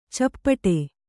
♪ cappaṭe